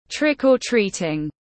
Cho kẹo hay bị ghẹo tiếng anh gọi là trick-or-treating, phiên âm tiếng anh đọc là /ˌtrɪk.ɔːˈtriː.tɪŋ/